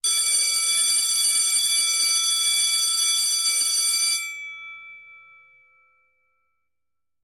На этой странице собраны разнообразные звуки школьного звонка: от традиционных резких переливов до современных мелодичных сигналов.
Школьный звонок – это для разнообразия (7 секунд)